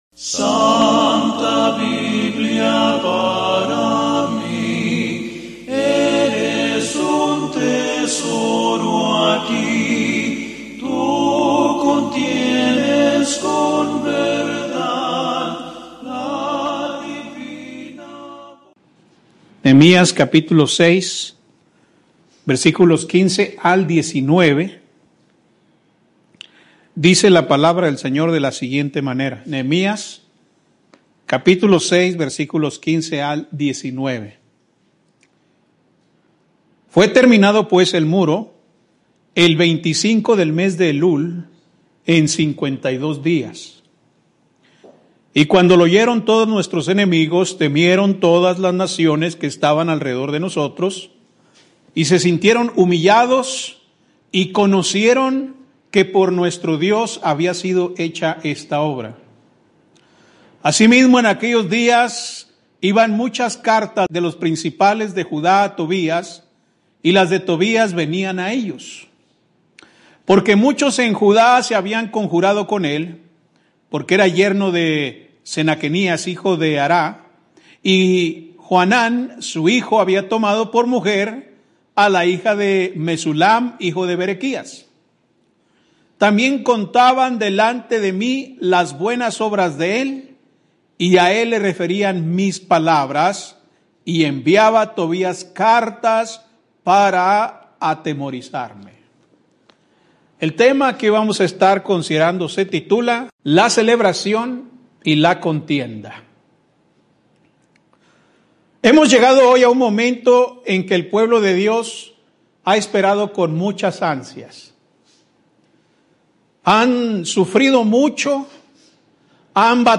Por eso, el pasaje que hoy estaremos meditando, puede resumirse en el título de nuestro sermón: La celebración y la contienda .